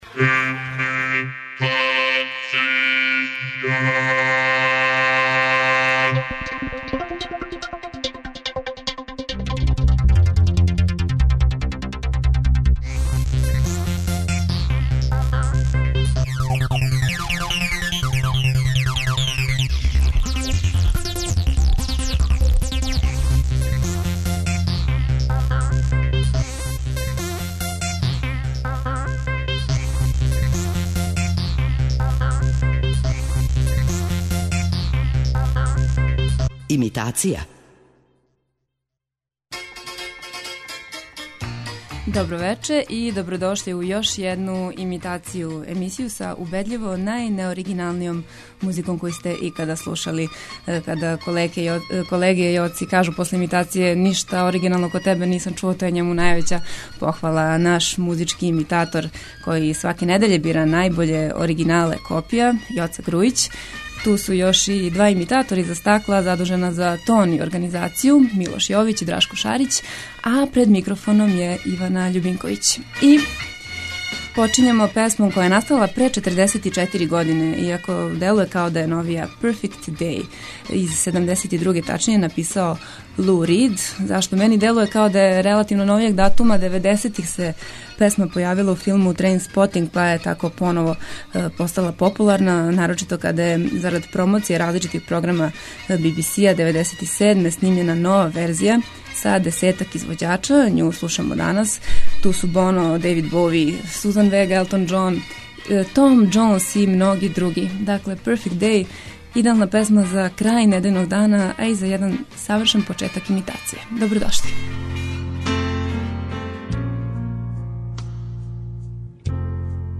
преузми : 28.42 MB Имитација Autor: Београд 202 Имитација је емисија у којој се емитују обраде познатих хитова домаће и иностране музике.